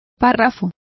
Complete with pronunciation of the translation of paragraphs.